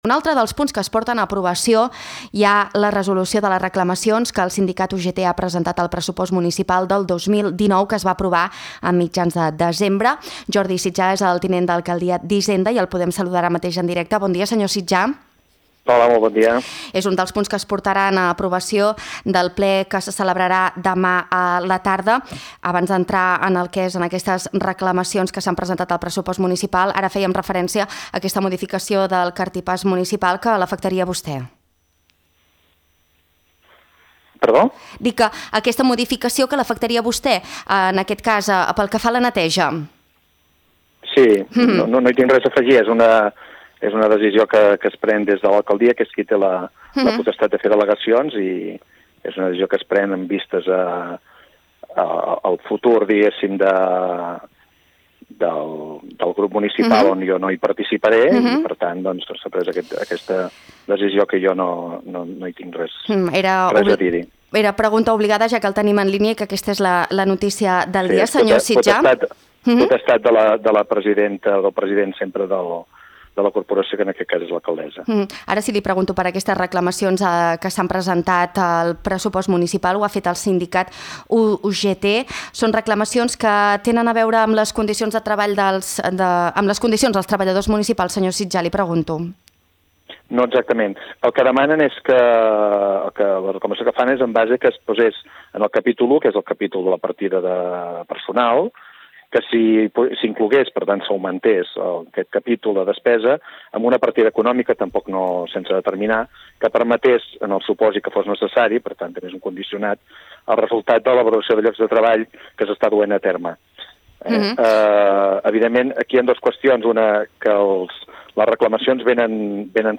El tinent d’Alcaldia d’Hisenda, Jordi Sitjà, ha avançat en una entrevista a l’Info Migdia que el sindicat ha demanat incloure una partida econòmica, sense determinar, que permeti respondre pressupostàriament al resultat de la valoració de llocs de treball que s’està fent.